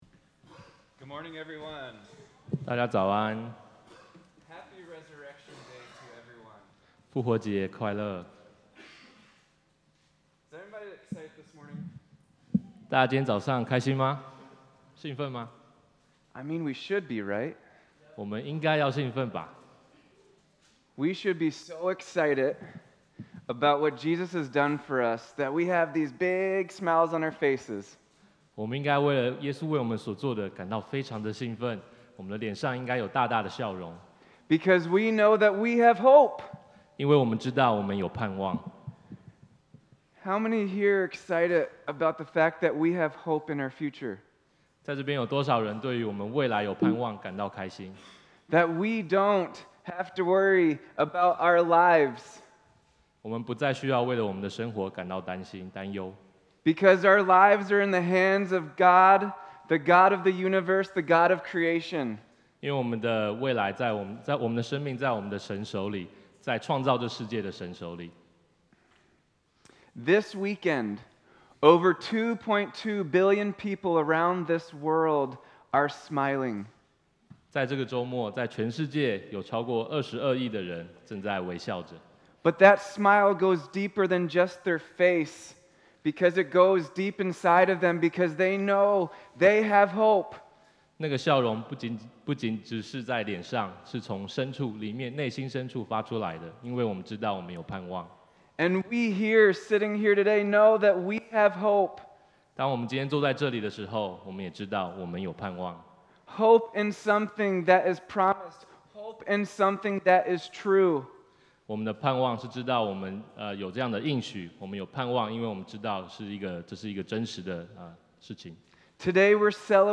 主日信息 – 第 33 页 – 拉法叶华人宣道会